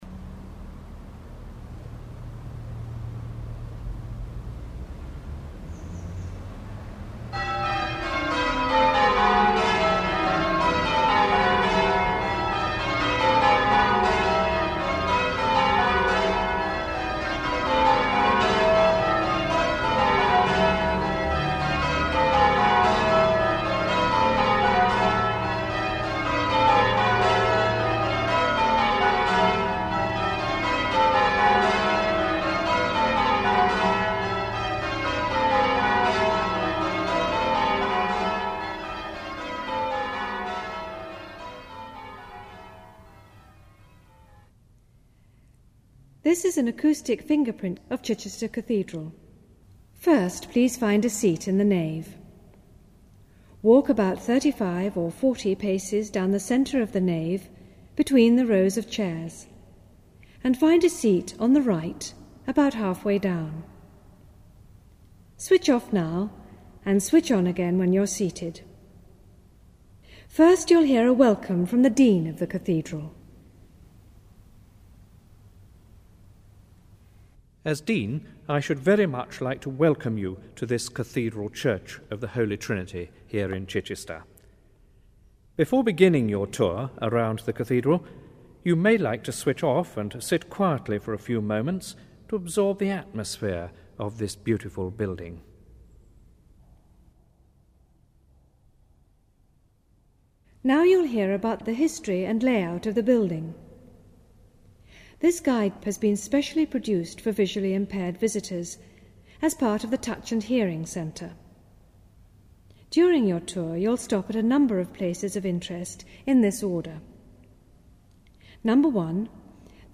An Acoustic Fingerprint Guide of Chichester Cathedral - Track 1: Bells, Introduction and Layout of the Cathedral
1-bells-introduction-layout-and-brief-history.mp3